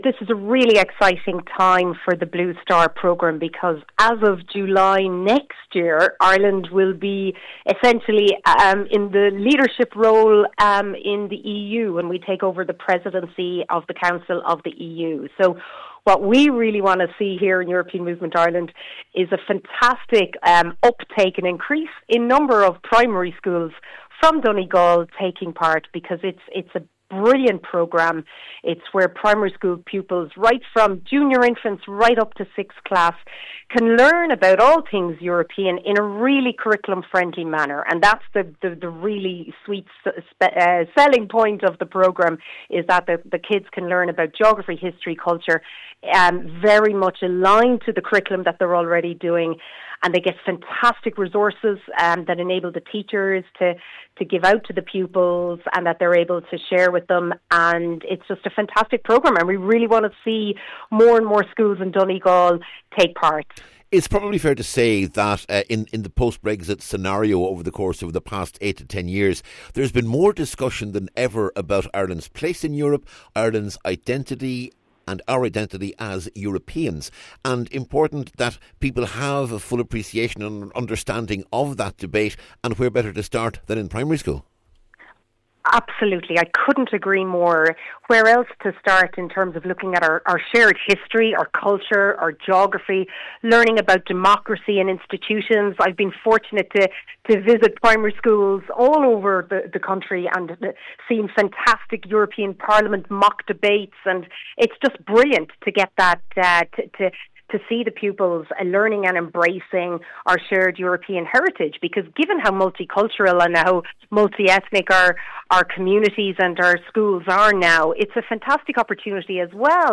Speaking ahead of the close of registrations on October 10th, she says 2026 will be a particularly important year, as Ireland takes on the EU Presidency in June……….